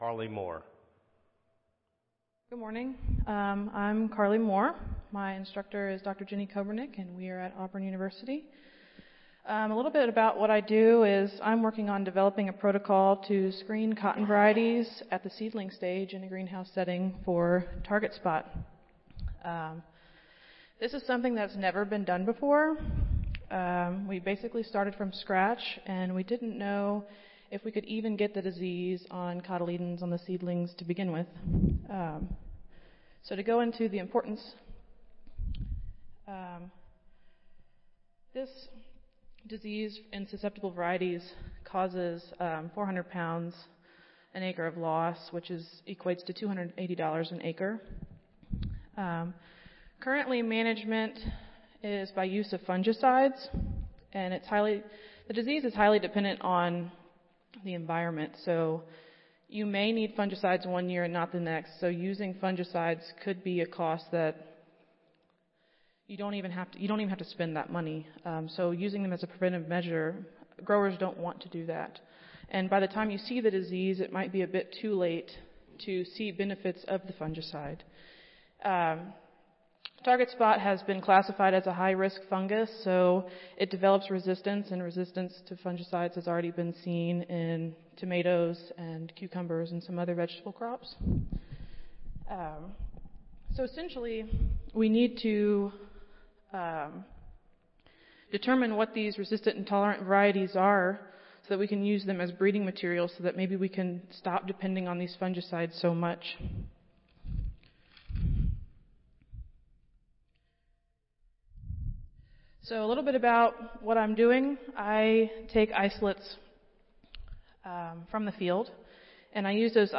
Cotton Improvement - Lightning Talk Student Competition
Audio File Recorded Presentation